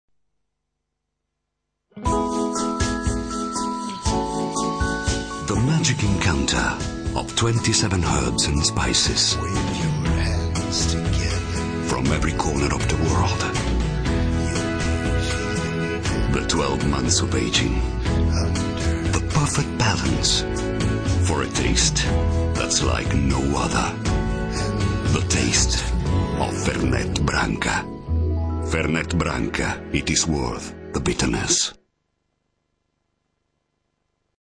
Caldo morbido